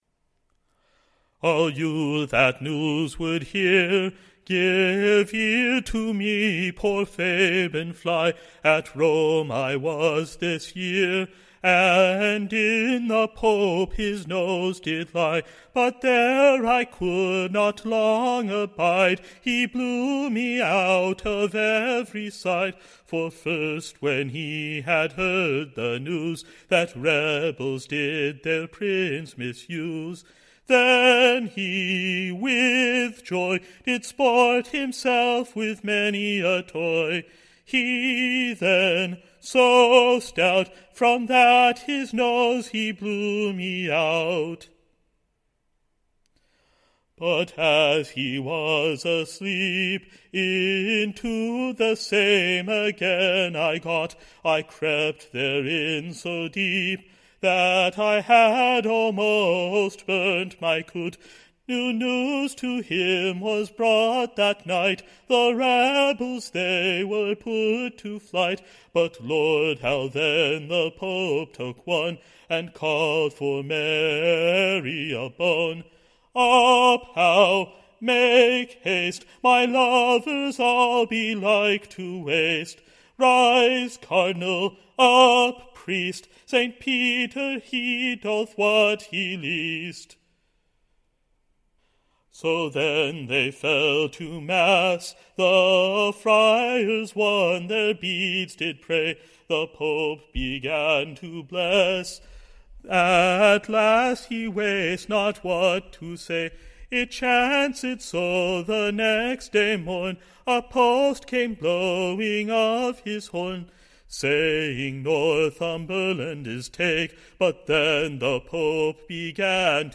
Recording Information Ballad Title A Lamentation from Rome, how the Pope doth / bewayle, That the Rebelles in England can not preuayle, Tune Imprint To the tune of Rowe well ye mariners.